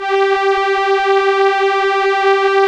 Index of /90_sSampleCDs/Keyboards of The 60's and 70's - CD1/STR_Elka Strings/STR_Elka Cellos
STR_ElkaVcG_5.wav